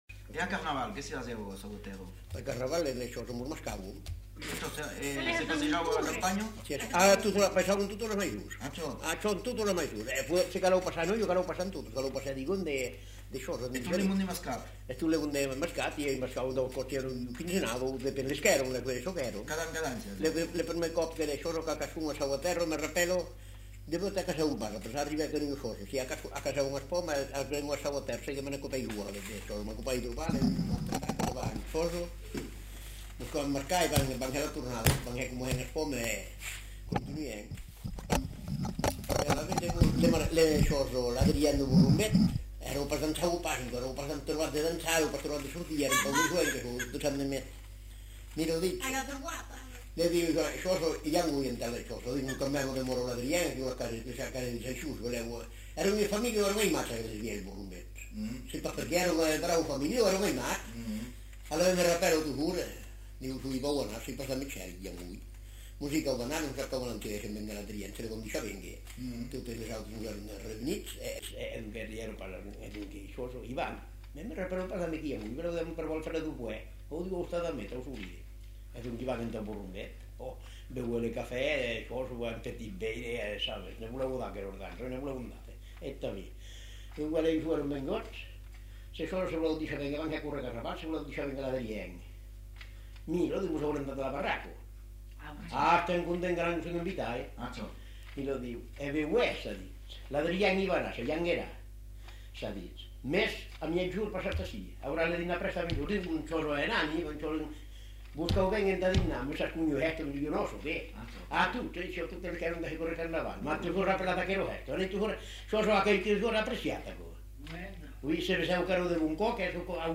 Lieu : Espaon
Genre : récit de vie